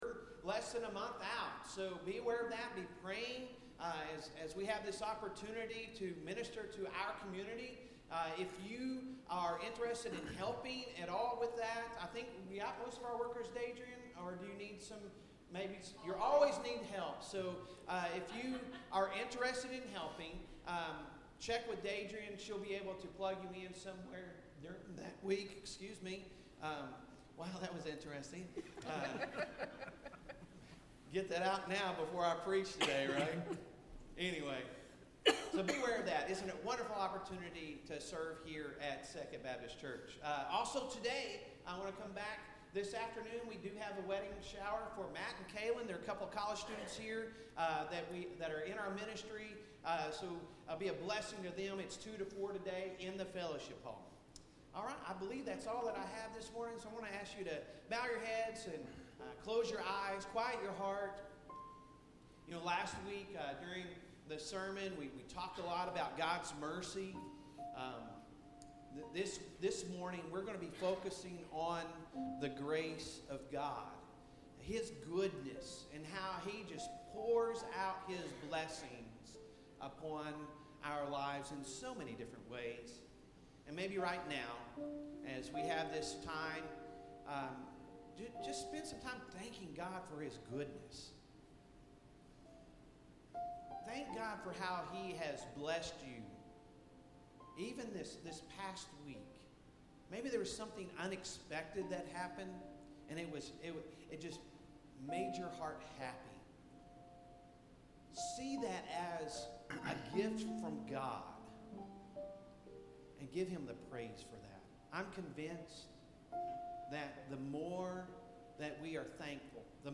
Sunday Sermon May 7, 2023